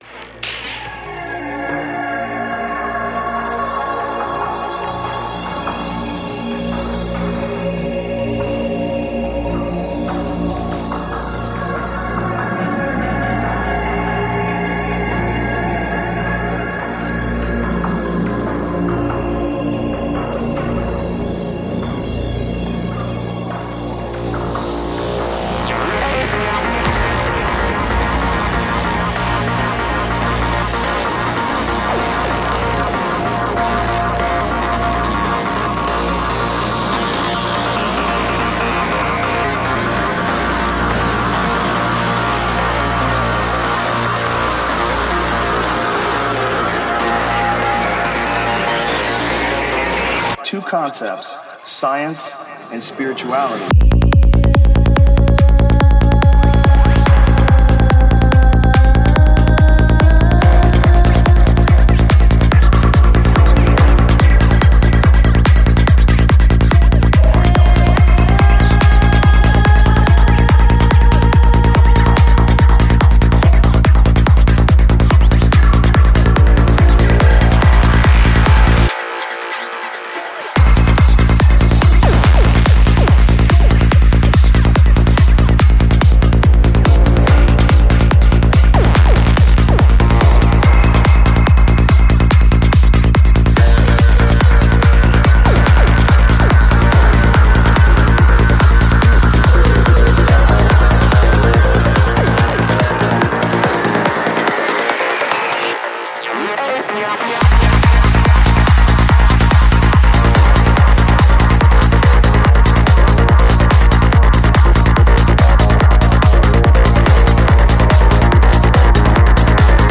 Жанр: Psychedelic
20:14 Альбом: Psy-Trance Скачать 7.36 Мб 0 0 0